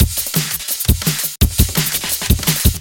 描述：低音和低音鼓循环 170 bpm
Tag: 170 bpm Drum And Bass Loops Drum Loops 486.44 KB wav Key : Unknown